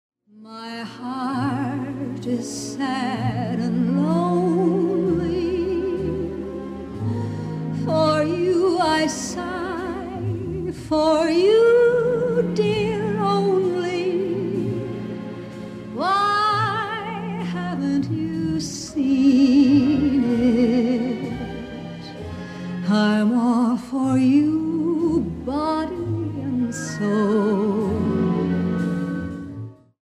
with the most exquisite phrasing and richness of tone
All the tracks have been digitally remastered.